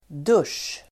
Uttal: [dusj:]